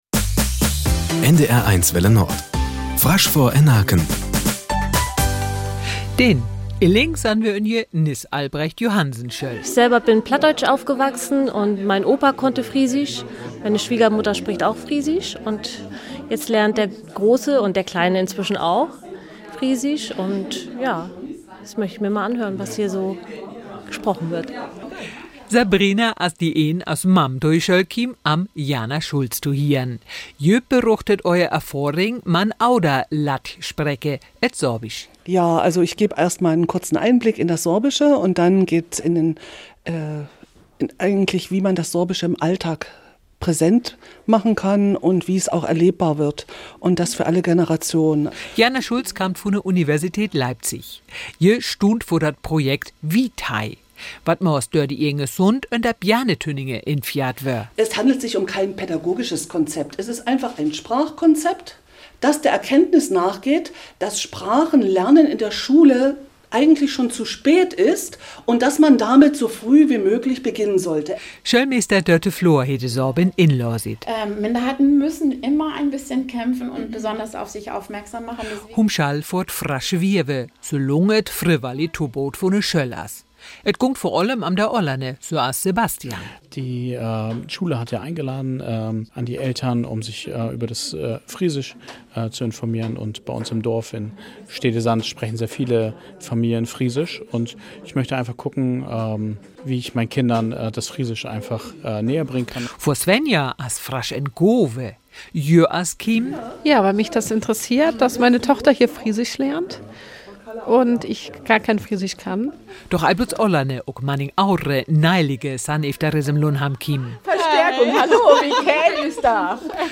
berichtet auf einem Elternabend in Risum-Lindholm